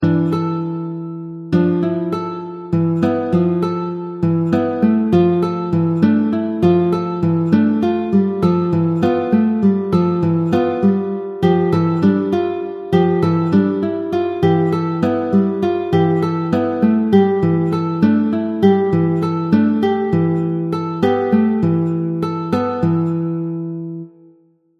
sous forme de musique minimaliste (cliquez sur cette image pour afficher la version PDF de 40 Ko)